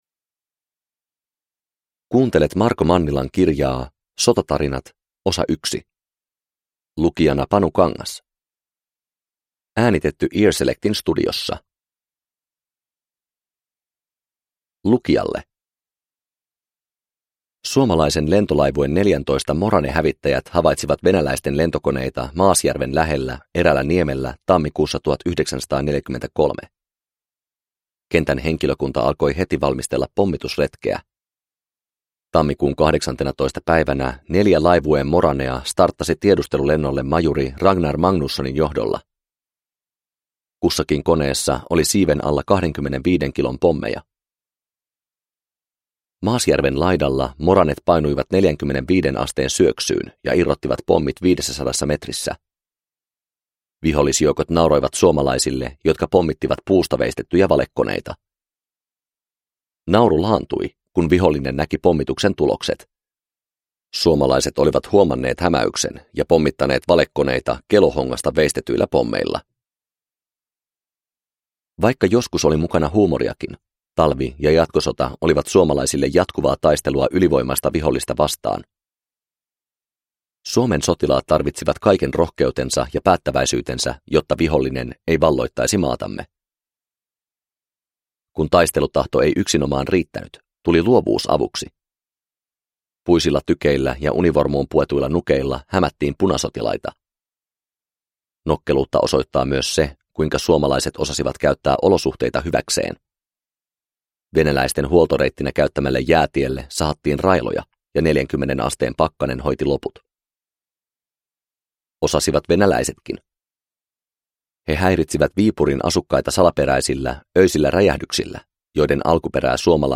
Sotatarinat 1 – Ljudbok – Laddas ner